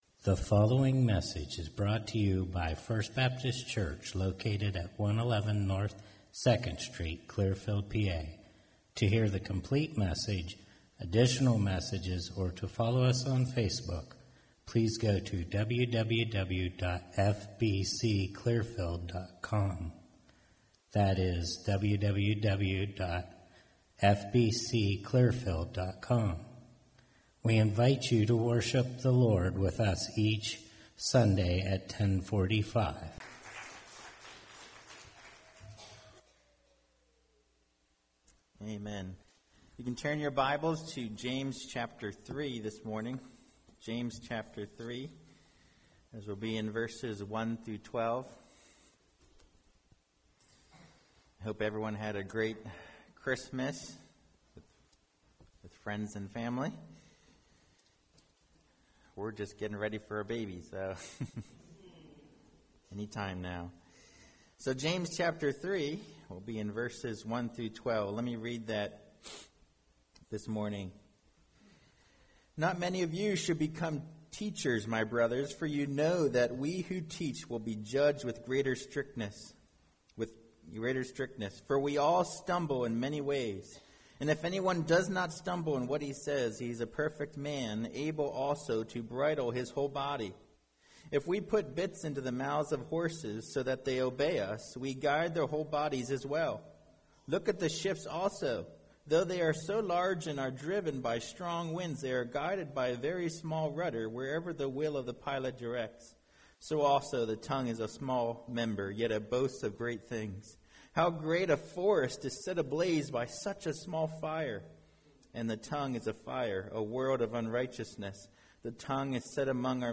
Non-Series Sermon Passage